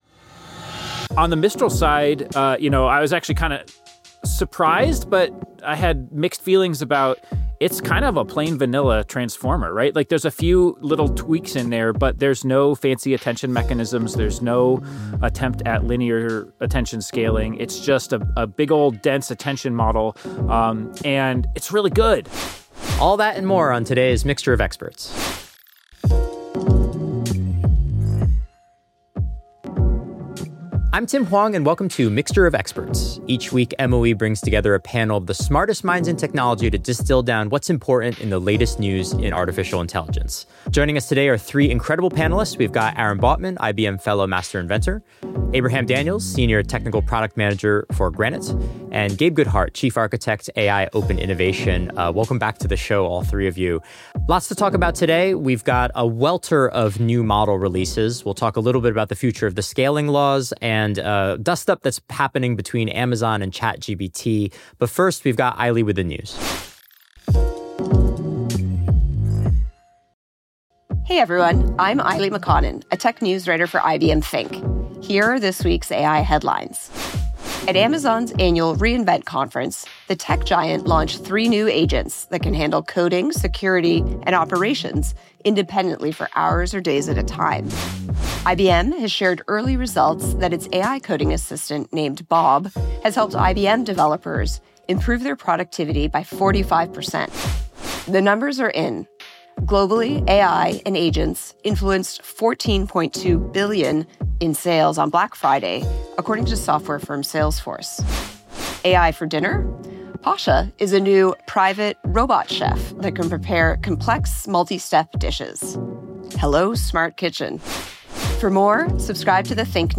Is open source winning the AI race? This week on Mixture of Experts, we analyze three major model releases that dropped in the final weeks of 2025: Mistral 3, DeepSeek-V3.2 and Claude Opus 4.5. Our experts discuss what makes each model unique—from Mistral’s multimodal capabilities to DeepSeek’s reasoning-first approach and Claude’s developer focus.